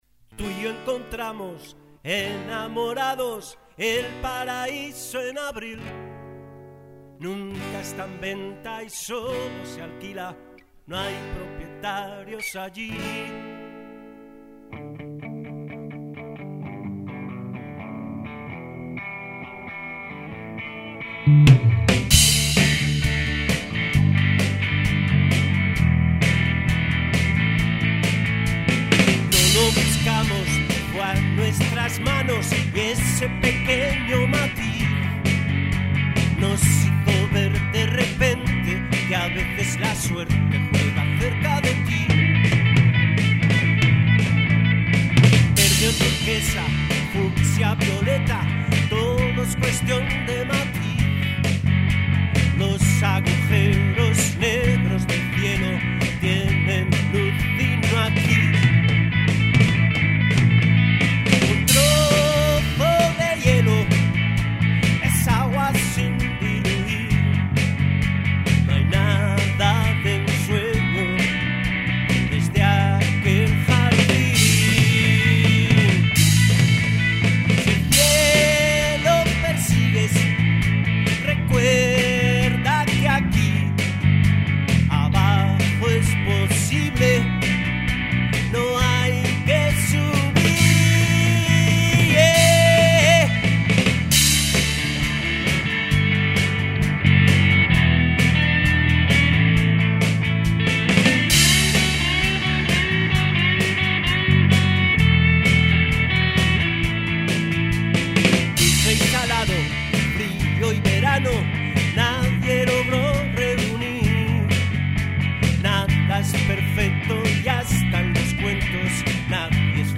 Guitarras eléctricas Gibson
Guitarra acústica
Batería
Bajo
Voz